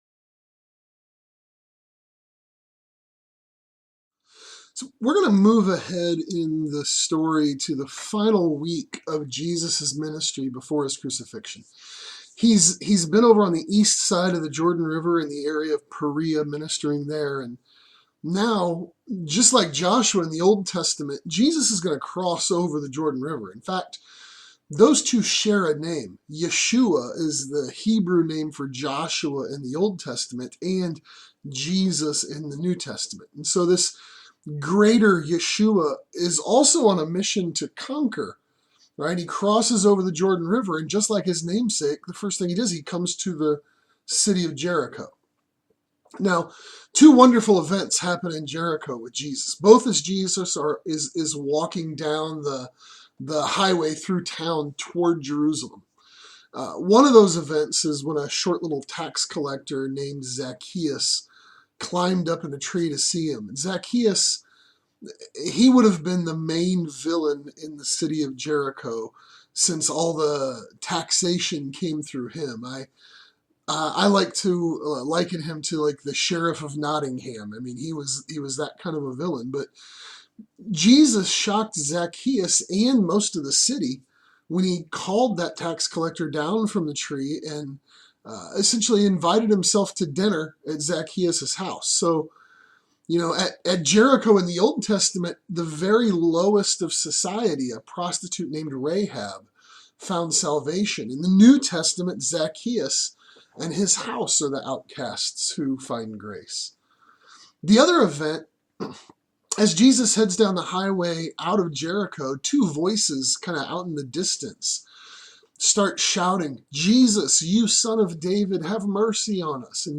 When The King Comes | SermonAudio Broadcaster is Live View the Live Stream Share this sermon Disabled by adblocker Copy URL Copied!